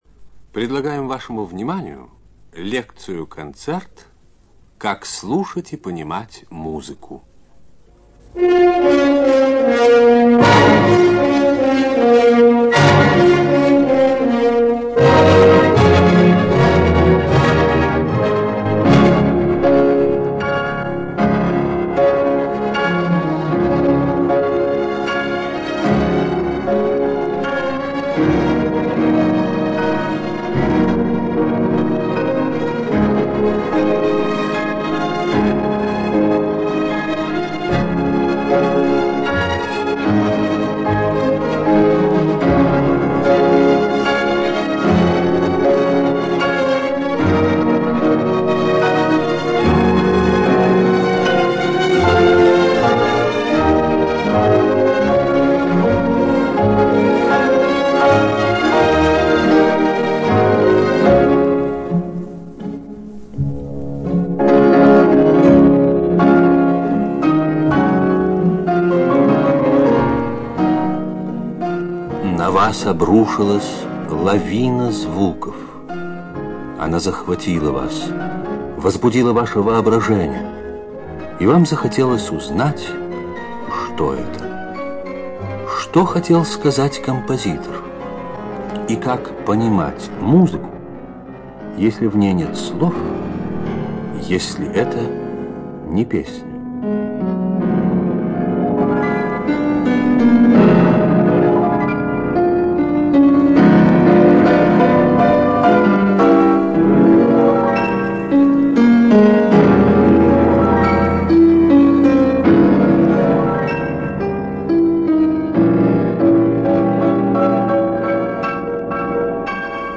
Литературно-музыкальный магнитофильм